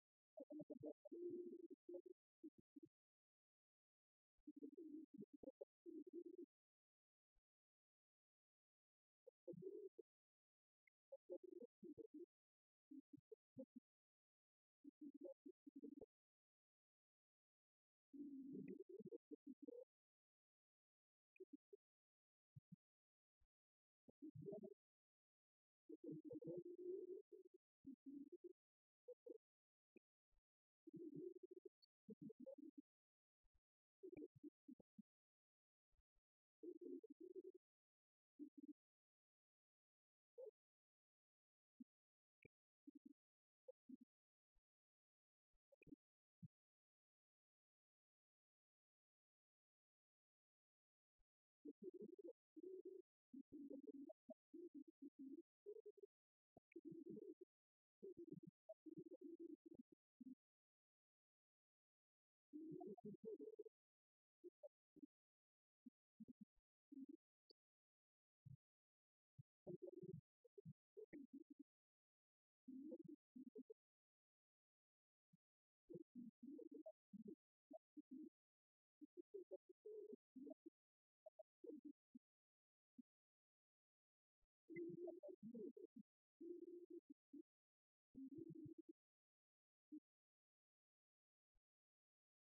abbaye de l'Epau
congrès, colloque, séminaire, conférence